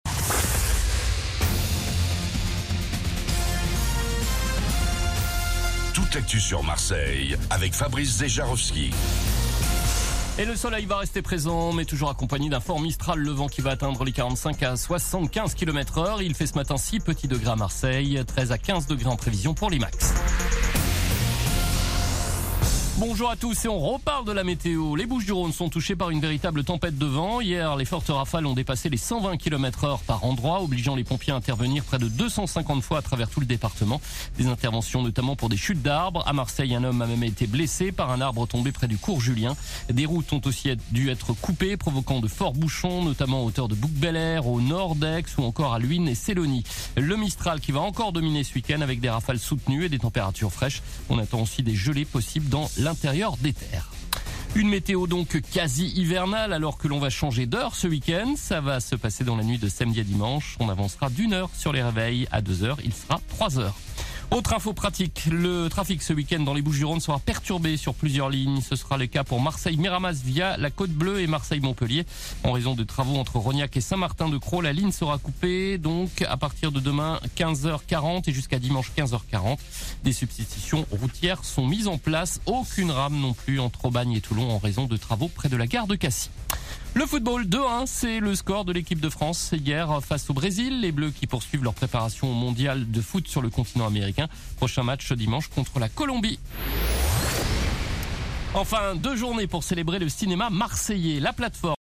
Actualités